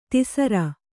♪ tisara